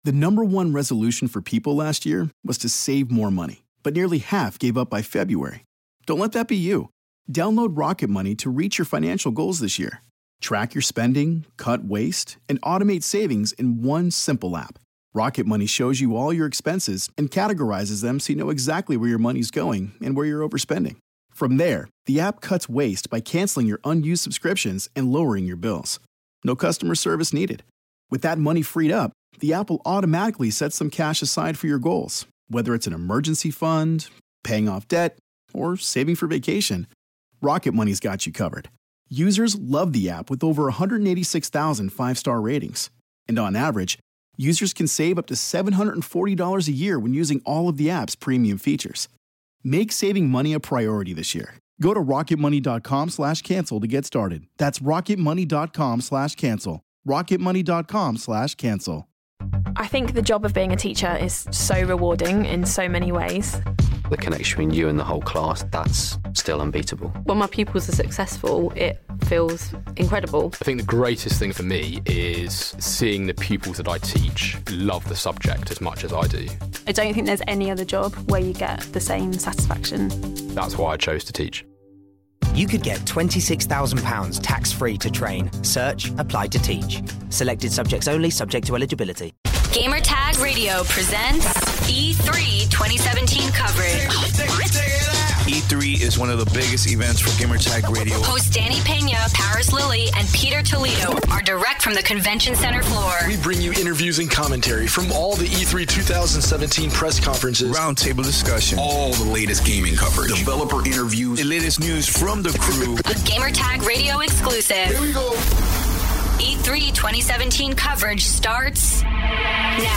roundtable discussion about Xbox, EA Play and Bethesda.